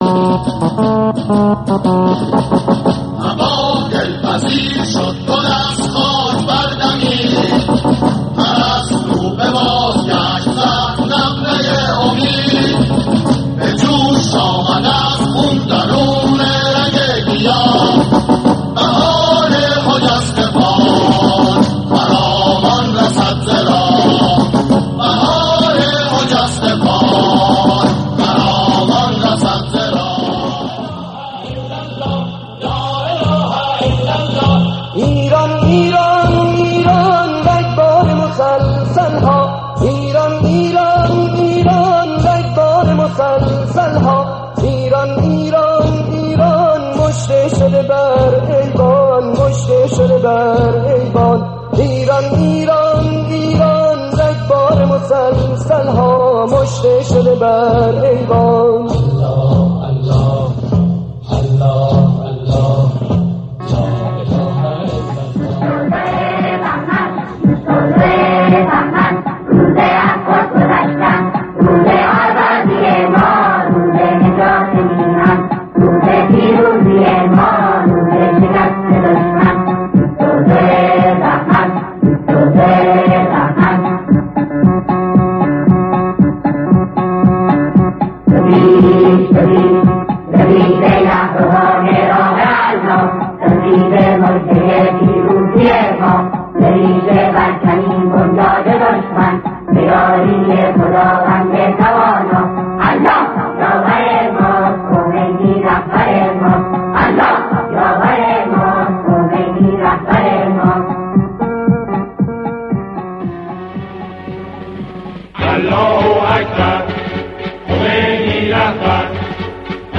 میکس موزیک انقلابی